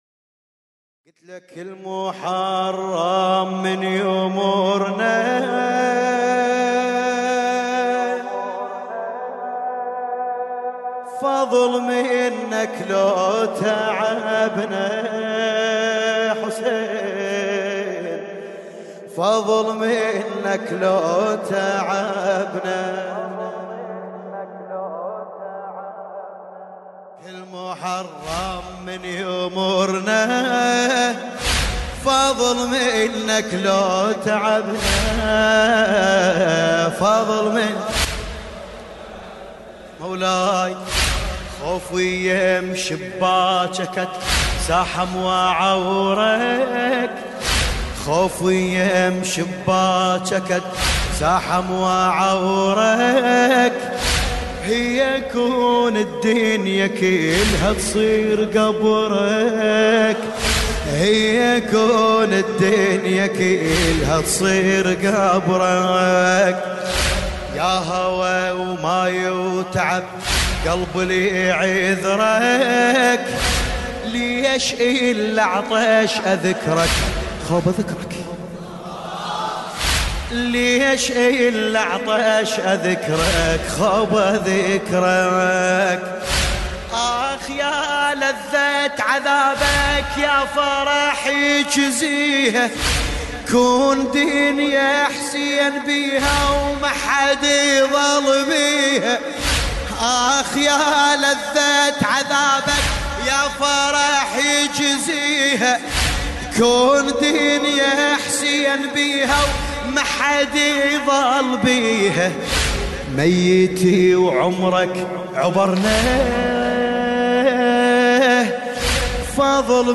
لطميات